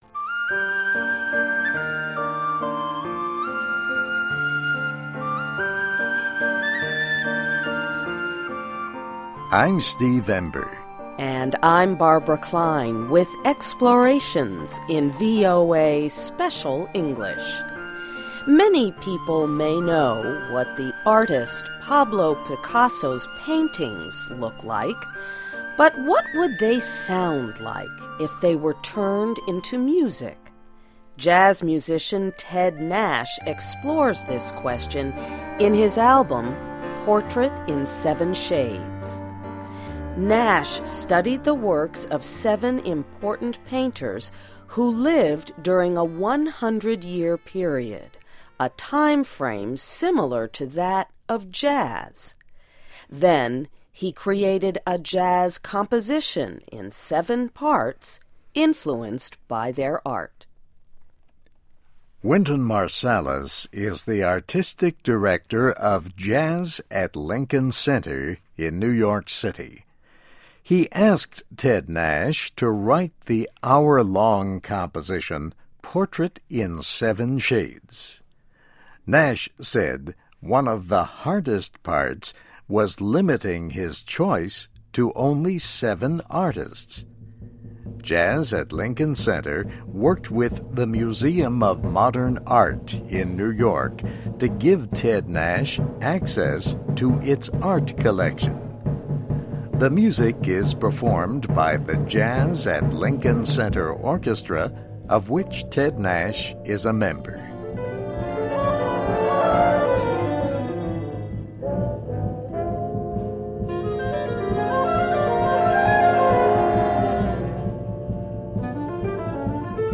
The music is performed by the Jazz at Lincoln Center Orchestra, of which Ted Nash is a member.